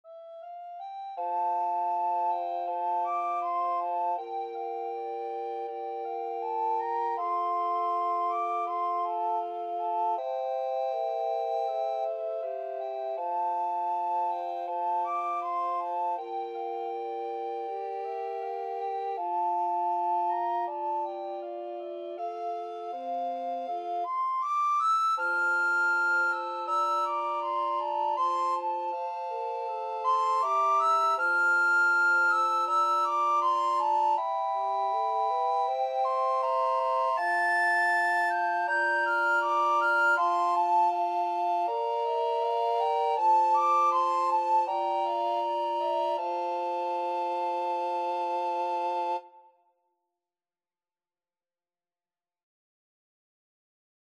Free Sheet music for Recorder Quartet
Soprano RecorderAlto RecorderTenor RecorderBass Recorder
4/4 (View more 4/4 Music)
Espressivo Andante
F major (Sounding Pitch) (View more F major Music for Recorder Quartet )
Recorder Quartet  (View more Easy Recorder Quartet Music)
Traditional (View more Traditional Recorder Quartet Music)
danny_boy_SATBREC.mp3